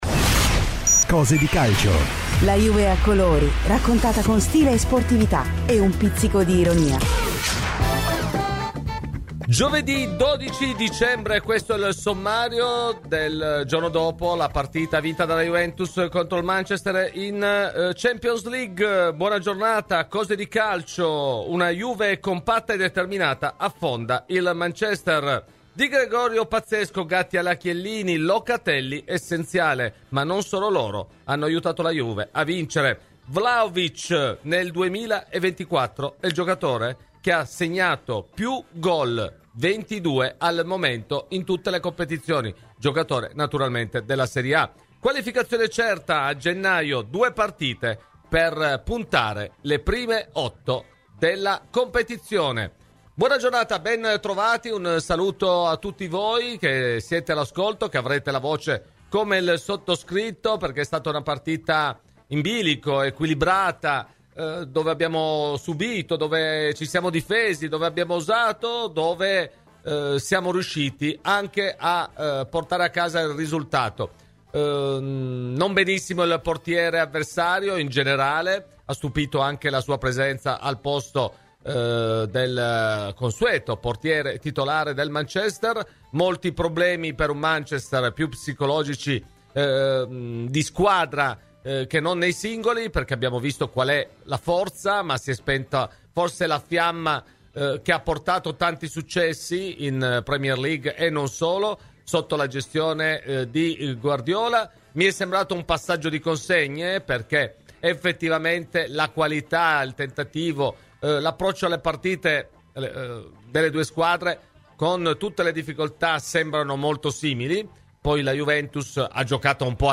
Il giornalista esperto di finanza ha spiegato poi a "Cose di Calcio" gli obblighi del club legati alla dichiarazione non finanziaria